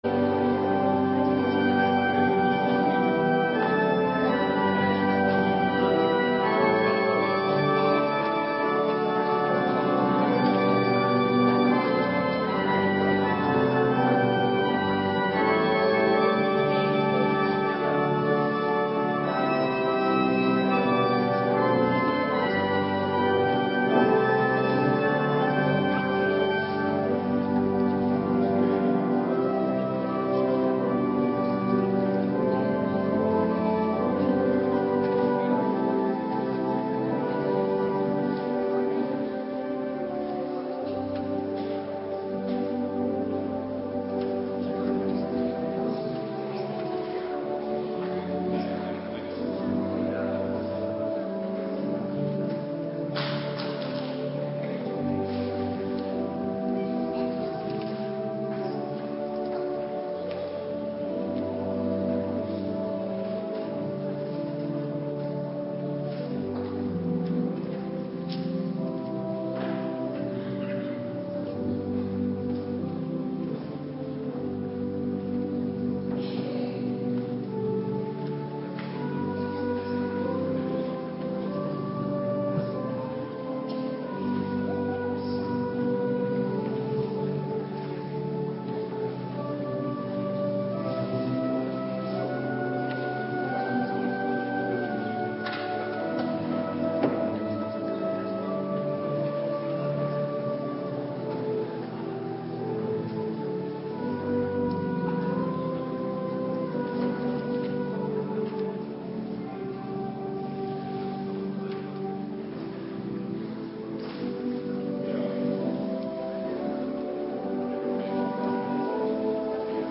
Morgendienst Hemelvaartsdag - Cluster 3
Locatie: Hervormde Gemeente Waarder